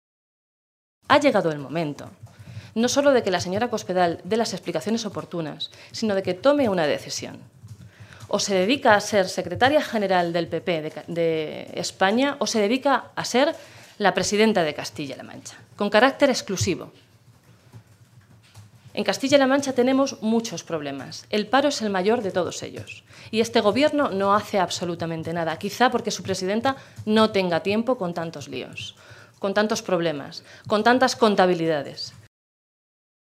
Blanca Fernández, diputada regional del PSOE de Castilla-La Mancha
Cortes de audio de la rueda de prensa